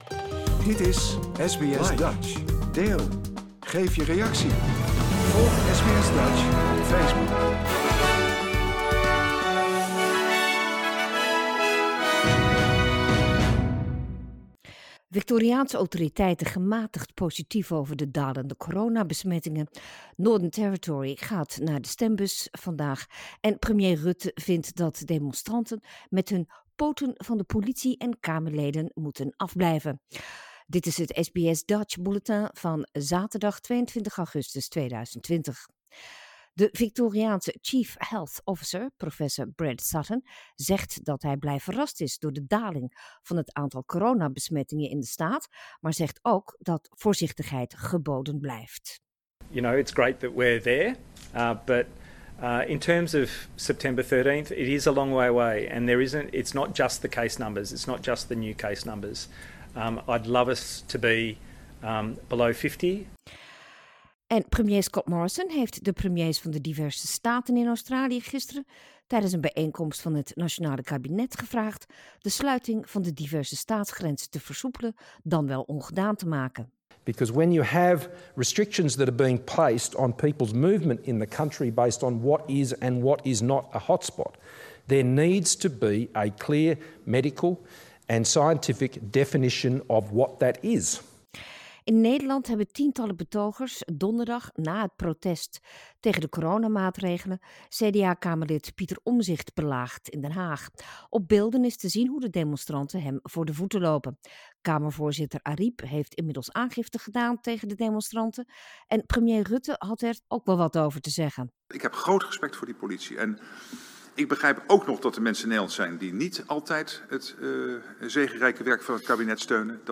Nederlands/Australisch SBS Dutch nieuwsbulletin zaterdag 22 augustus 2020